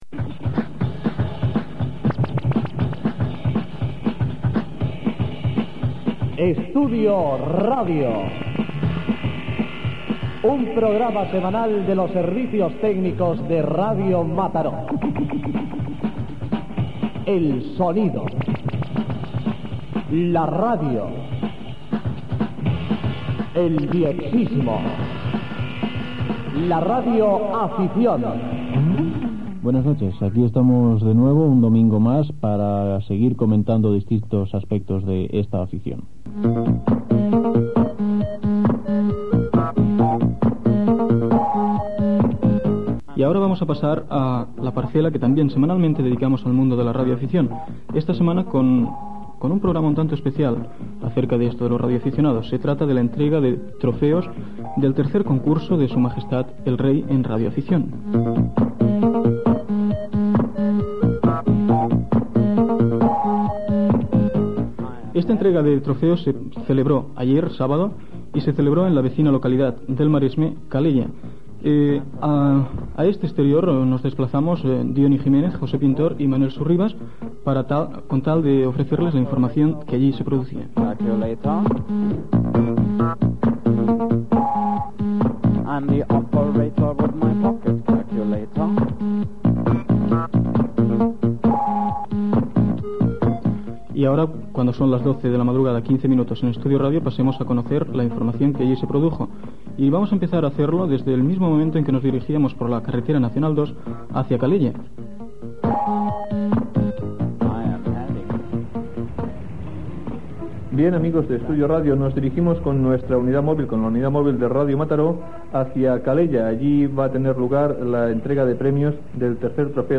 Inici del programa.
Divulgació
FM